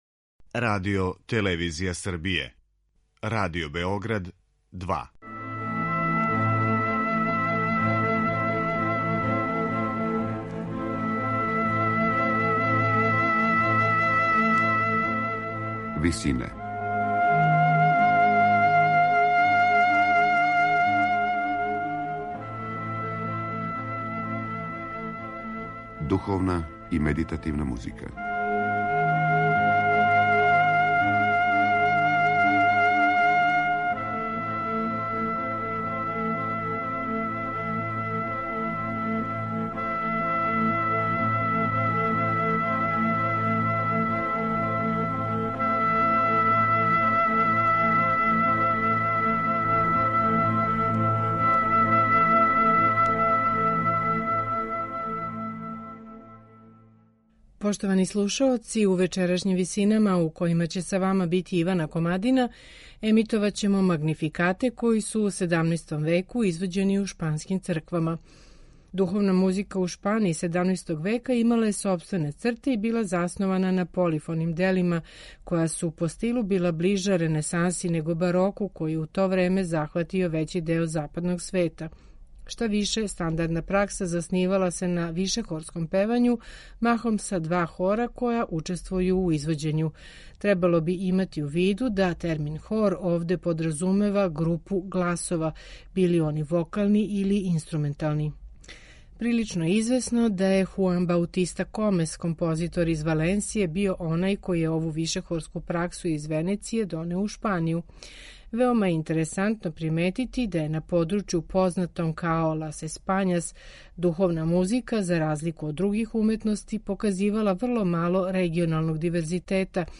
Духовна музика у Шпанији XVII века имала је сопствене, особене црте и била заснована на полифоним делима, која су по стилу била ближа ренесанси него бароку,а он је у то времезахватио већи део западног света.
Шпански магнификати XVII века
Штавише, стандардна пракса базирала се на вишехорском певању, махом са два хора који учествују у извођењу.